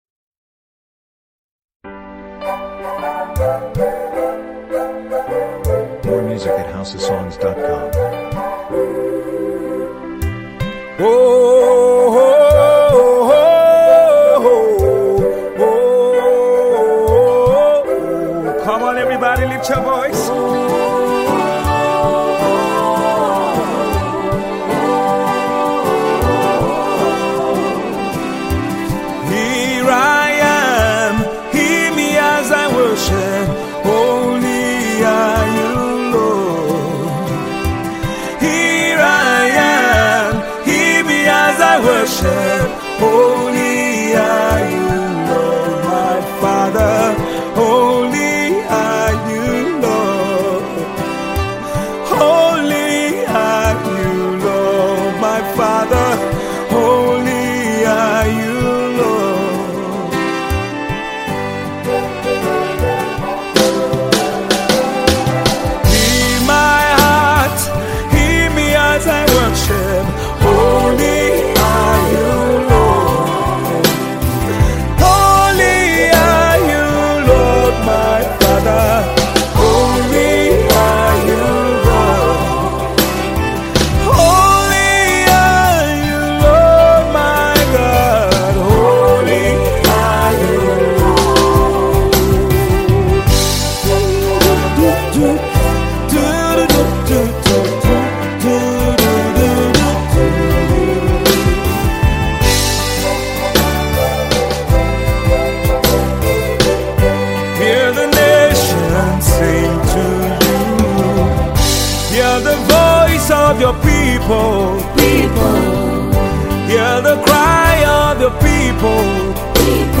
Your desired Tiv song